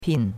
pin4.mp3